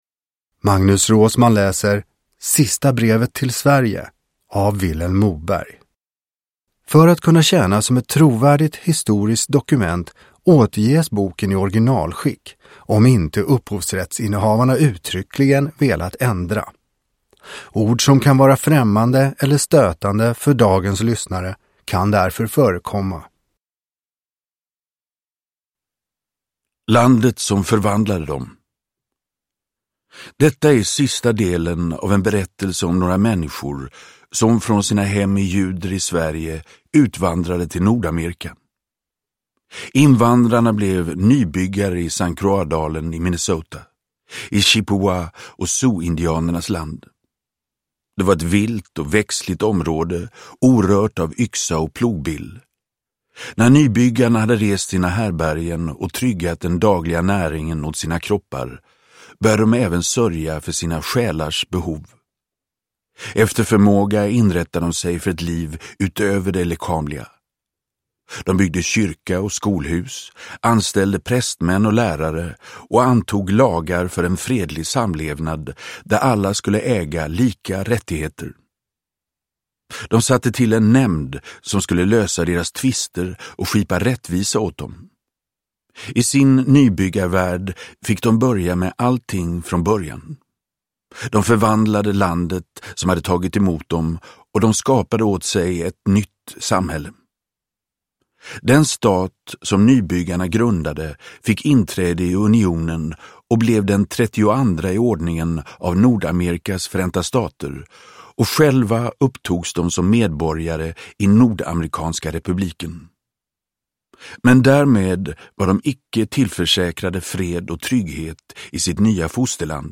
Sista brevet till Sverige – Ljudbok – Laddas ner
Uppläsare: Magnus Roosmann